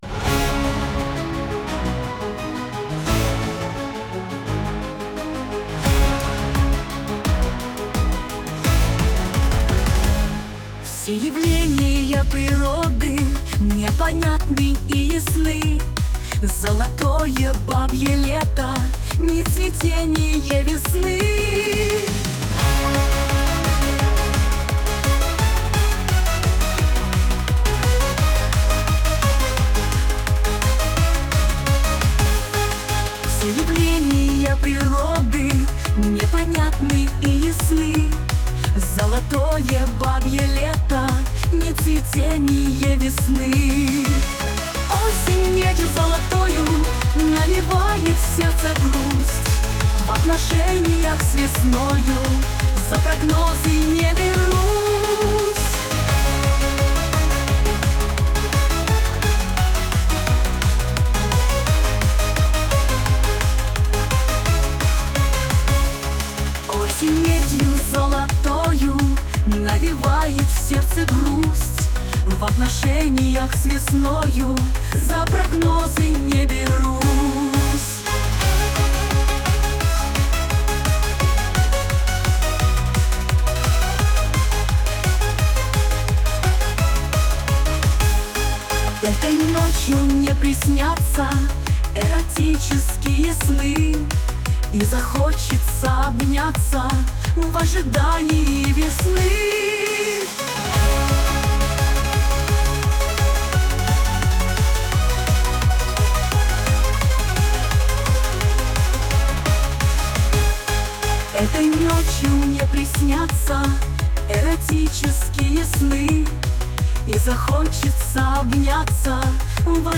Музыкальный хостинг: /Бардрок